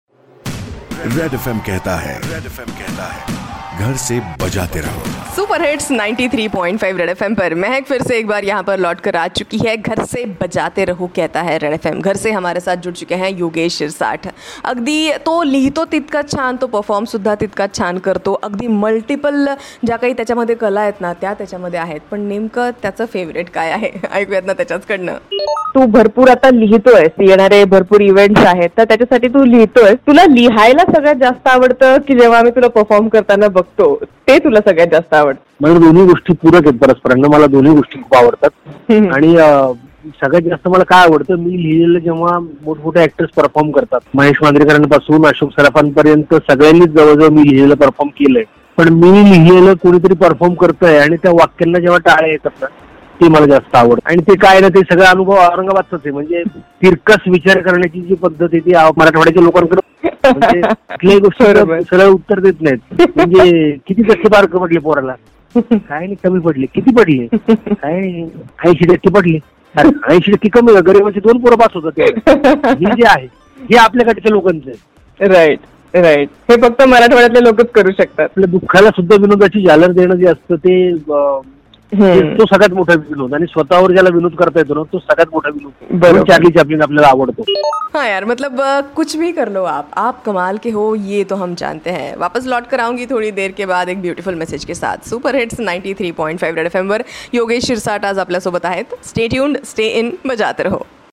marathi actor, writer, comedian on the occasion of world laughter day